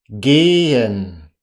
เกห์-เฮ็น